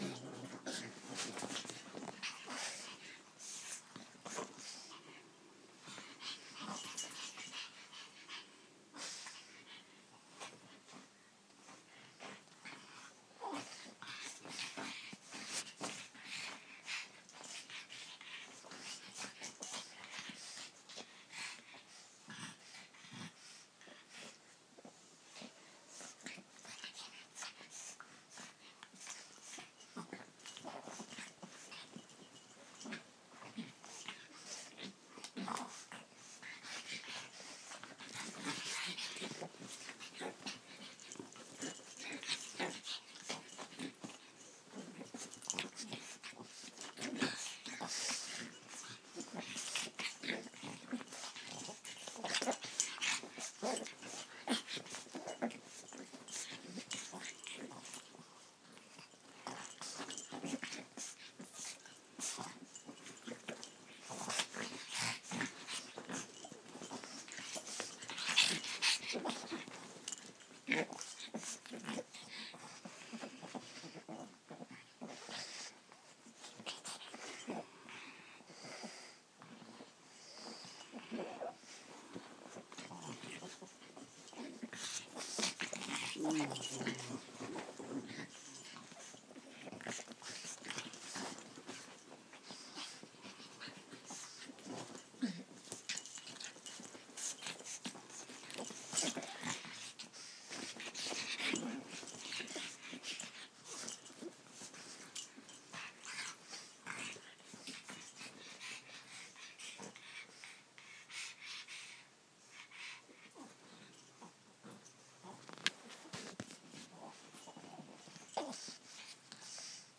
2 Pugs Playing